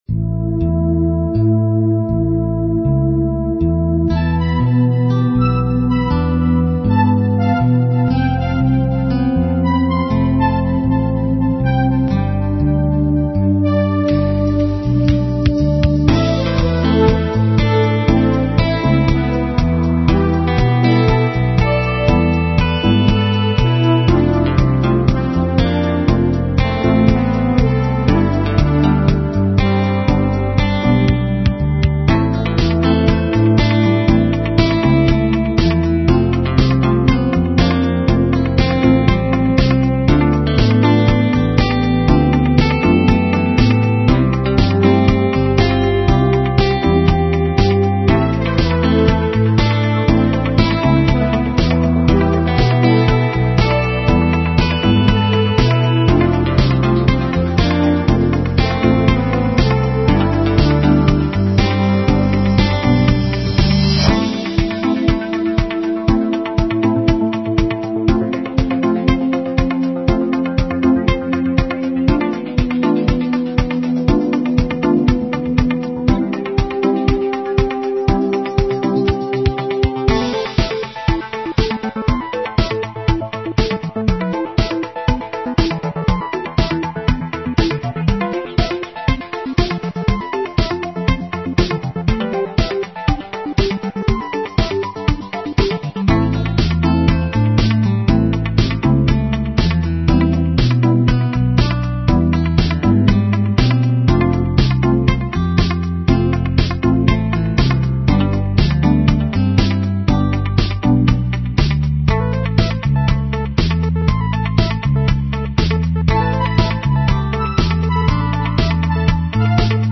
Cinematic Instrumental Rock